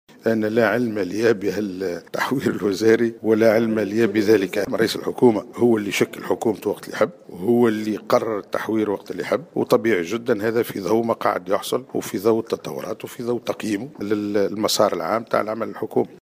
وأضاف في تصريح لمراسل الجوهرة اف ام اليوم الاثنين خلال الندوة الإقليمية للاستشارة الوطنية الرابعة حول مشروع الإستراتيجية لتحديث الإدارة والوظيفة العمومية، أن رئيس الحكومة يوسف الشاهد هو فقط من له صلاحيات إجراء تحوير وزاري، انطلاقا من تقييمه لعمل فريقه الحكومي وتطورات البلاد.